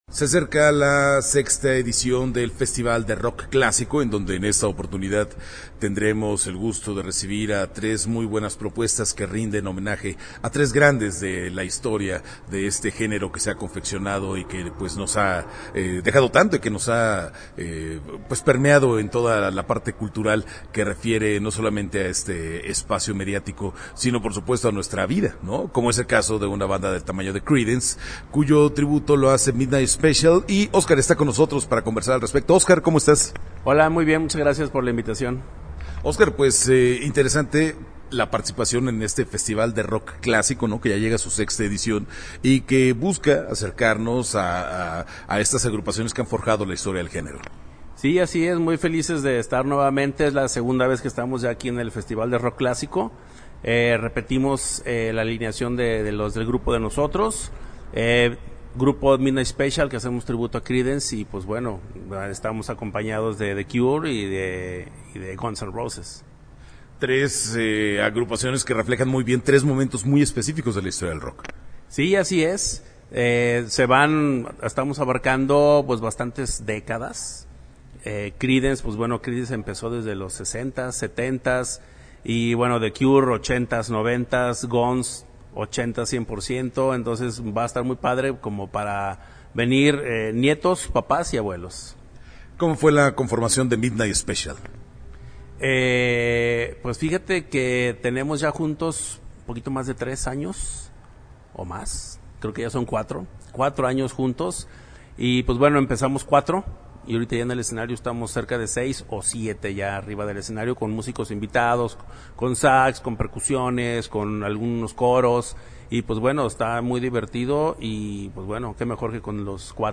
Entrevista-Midnight-Special-Tributo-Creedence-web.mp3